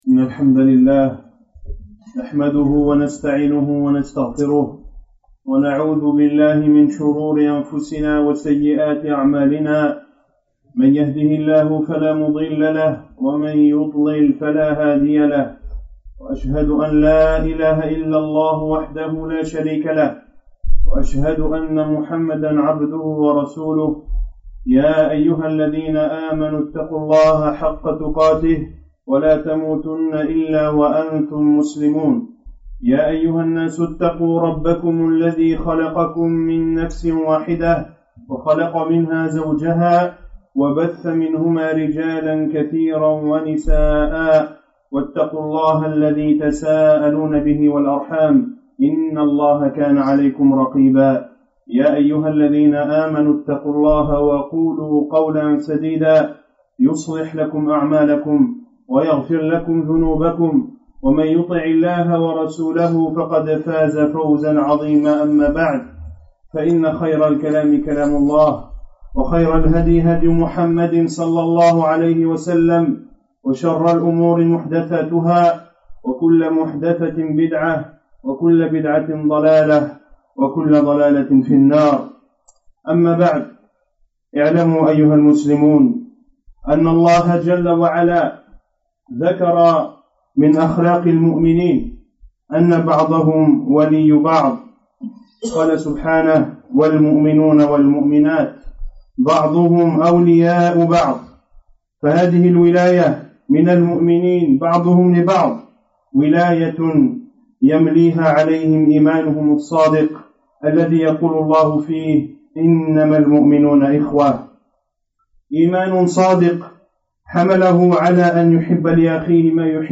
Ce discours du vendredi